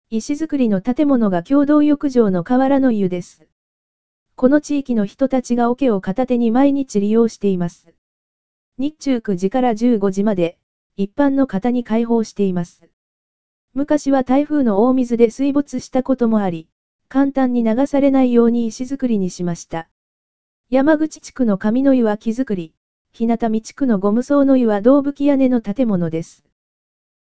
河原の湯 – 四万温泉音声ガイド（四万温泉協会）